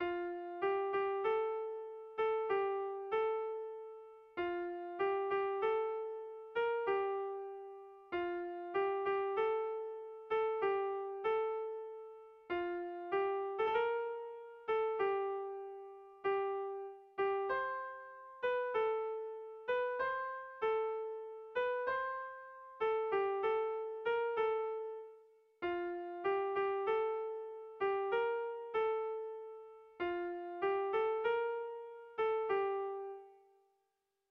Air de bertsos - Voir fiche   Pour savoir plus sur cette section
Tragikoa
Zortziko txikia (hg) / Lau puntuko txikia (ip)
A1A2BA2